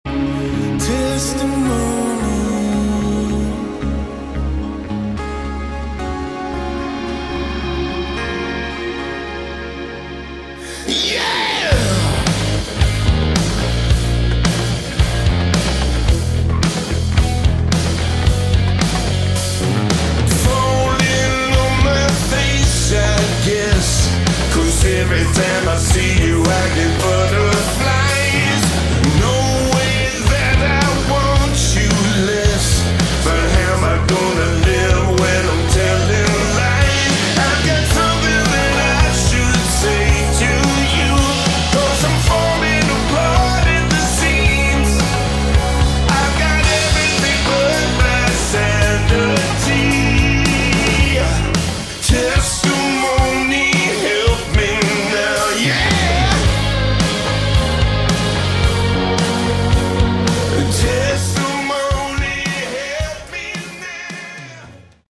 Category: AOR / Melodic Rock
lead vocals
guitars